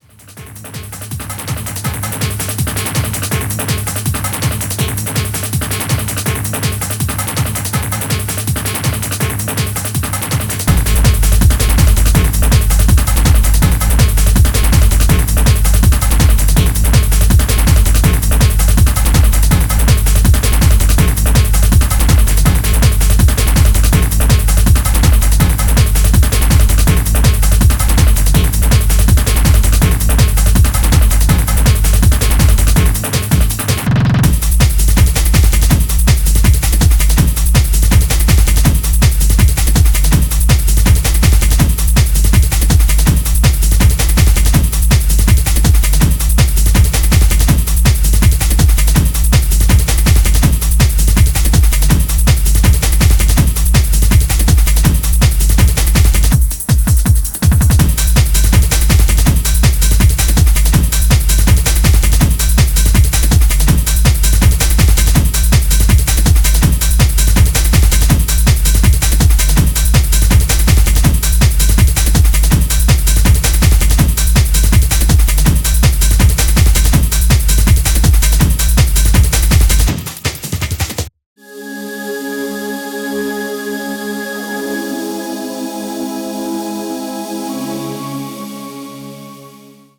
Electronix House Techno Bass Jungle